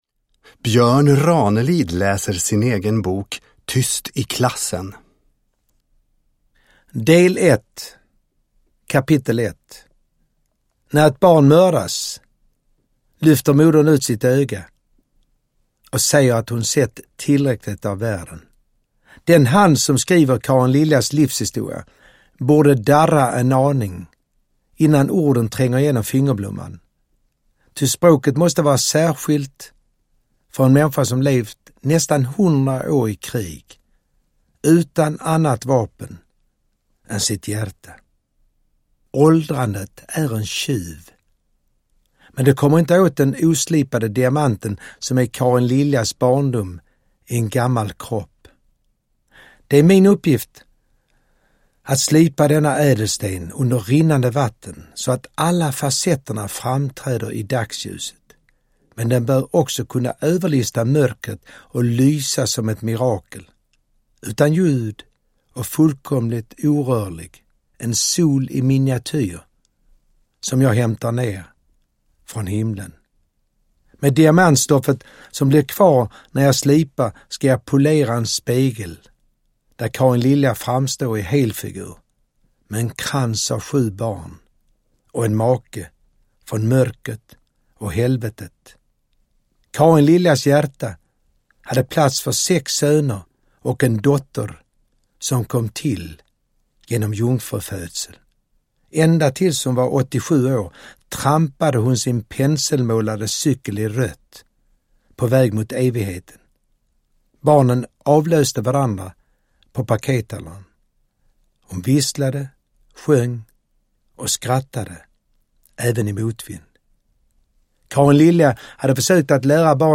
Uppläsare: Björn Ranelid